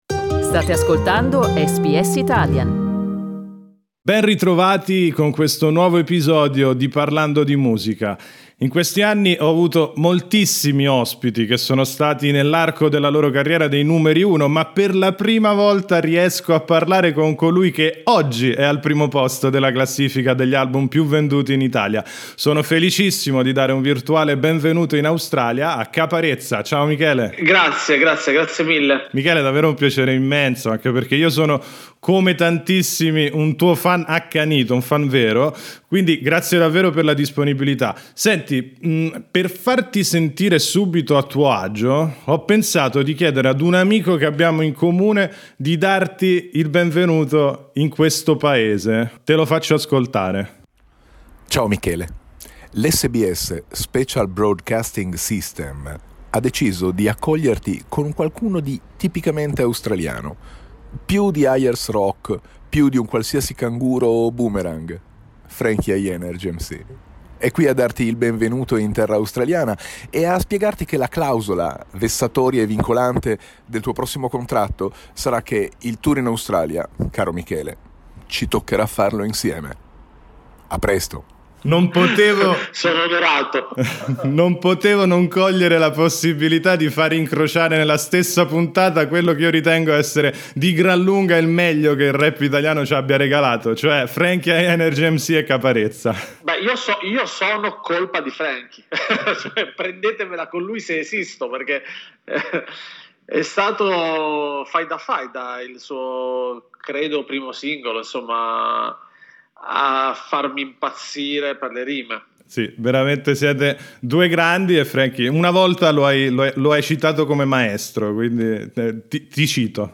In questa chiacchierata con SBS Italian